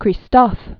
(krē-stôf), Henri 1767-1820.